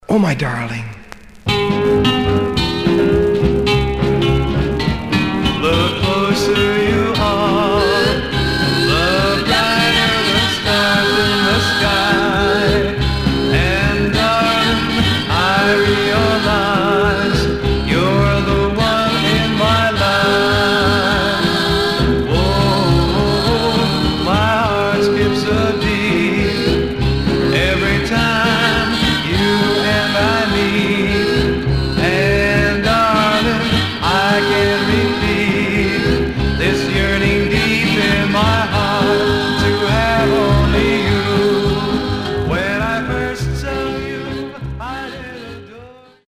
Surface noise/wear Stereo/mono Mono